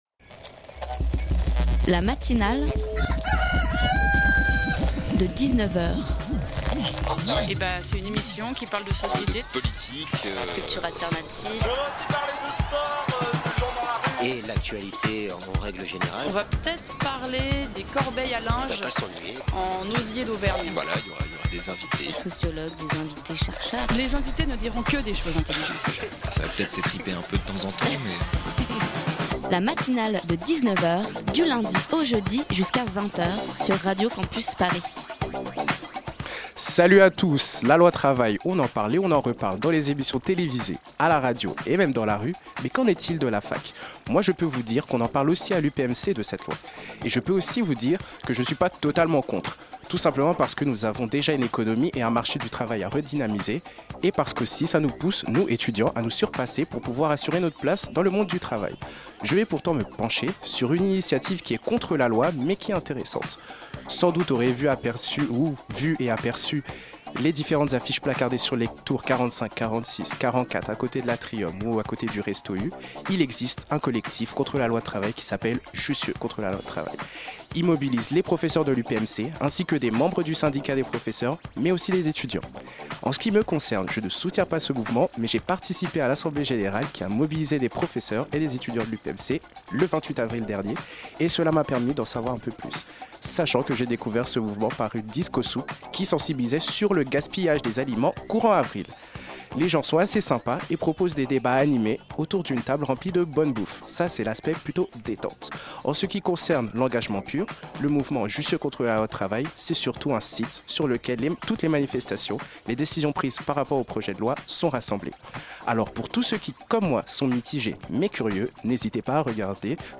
Après 10 Ateliers de formation aux techniques radiophoniques, les étudiants de l'UPMC - Paris 6 ont enregistré l'émission "Fac & Co" dans les conditions du direct dans les studios de Radio Campus Paris.